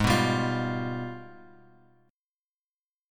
AbmM13 Chord
Listen to AbmM13 strummed